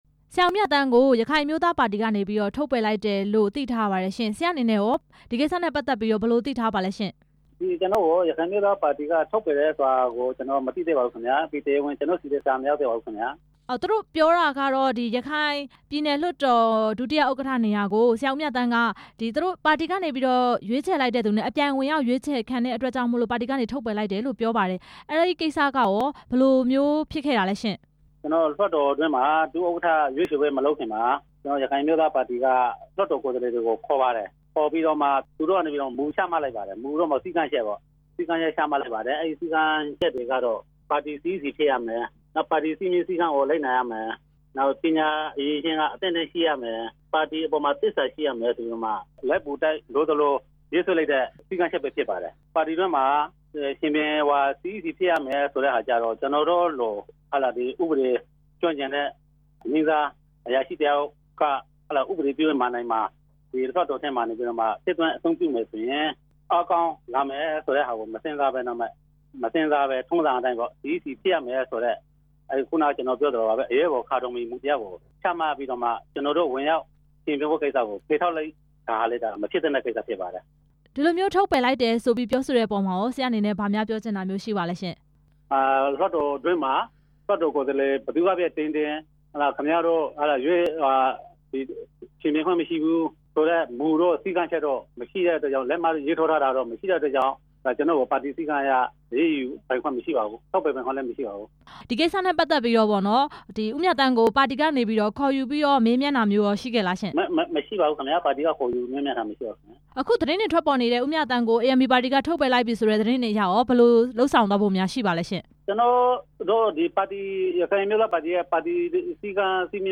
ရခိုင်ပြည်နယ်လွှတ်တော် ဒုတိယ ဥက္ကဌ ဦးဦးမြသန်း နဲ့ မေးမြန်းချက်